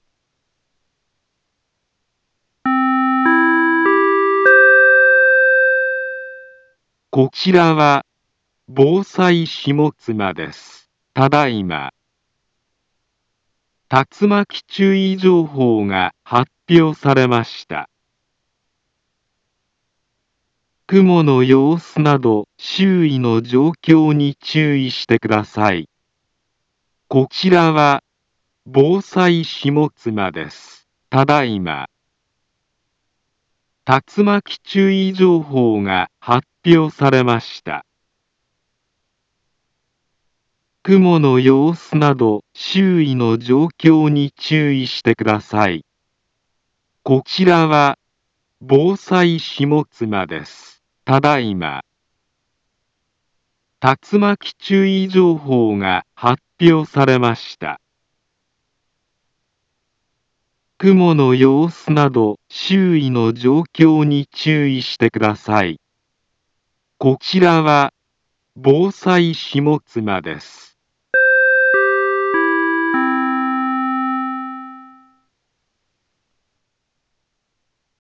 Back Home Ｊアラート情報 音声放送 再生 災害情報 カテゴリ：J-ALERT 登録日時：2023-09-08 10:59:51 インフォメーション：茨城県南部は、竜巻などの激しい突風が発生しやすい気象状況になっています。